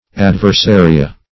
Search Result for " adversaria" : The Collaborative International Dictionary of English v.0.48: Adversaria \Ad`ver*sa"ri*a\, n. pl.
adversaria.mp3